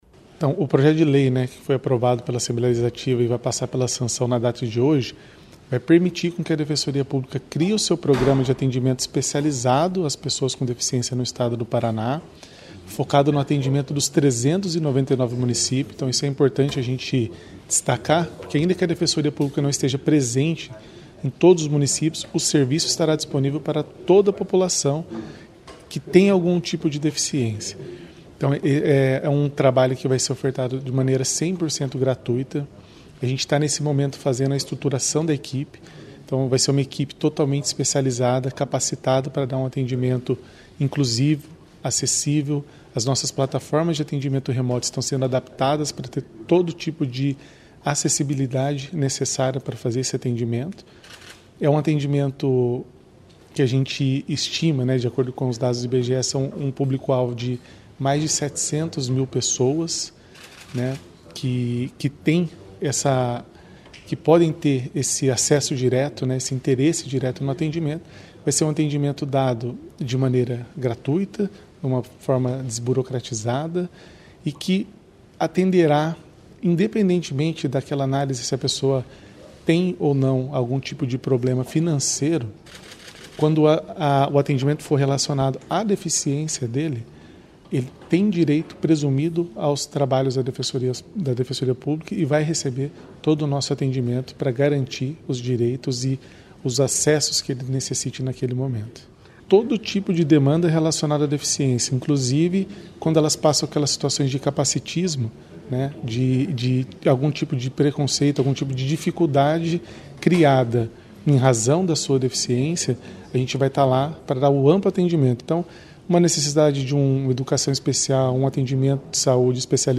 Sonora do defensor público-geral do Paraná, Matheus Cavalcanti Munhoz sobre o sancionamento da lei que garante atendimento gratuito da Defensoria Pública para as PcDs